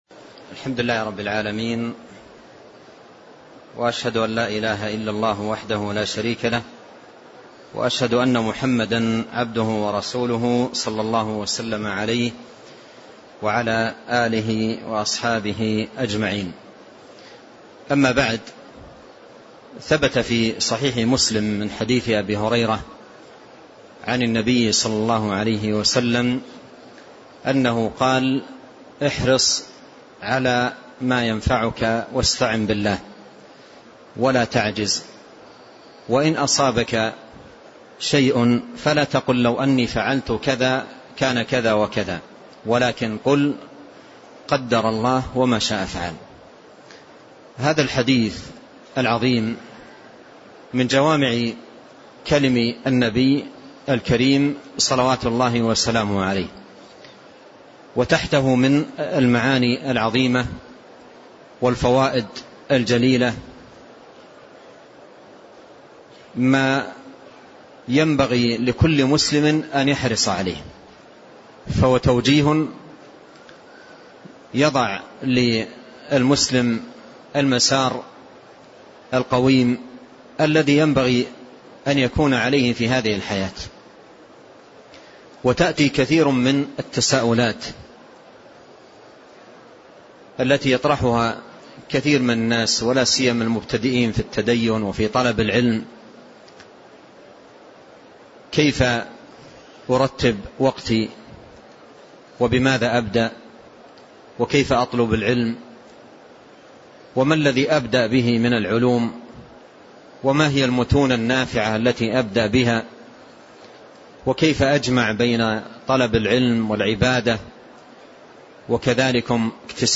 شرح الفتاوى السعدية الدرس 1 شرح حديث احرص على ما ينفعك واستعن بالله